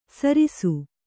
♪ sarisu